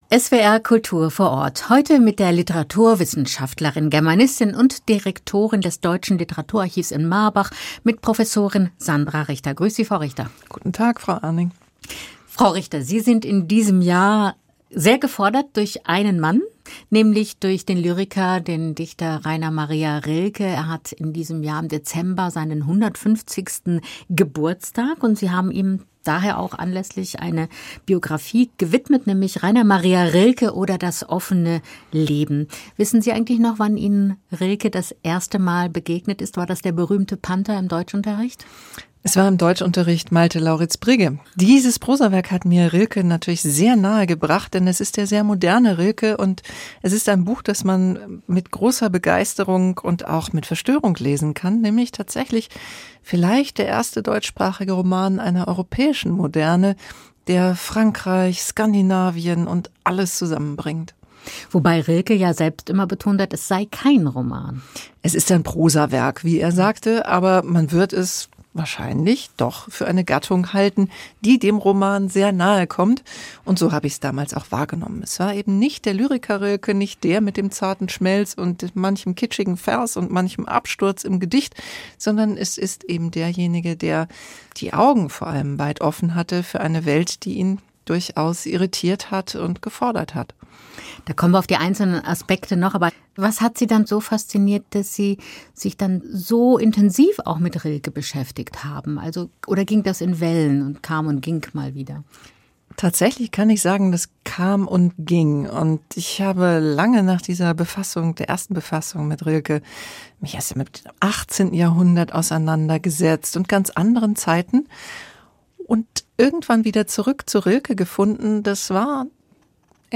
Rainer Maria Rilke – Ein offenes Leben | Gespräch